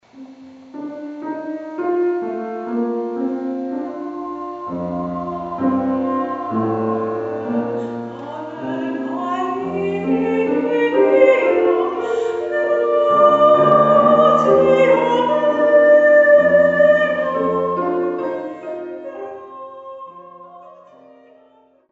zpěv a varhany (klavír)